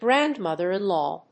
grandmother-in-law.mp3